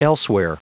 Prononciation du mot elsewhere en anglais (fichier audio)
Prononciation du mot : elsewhere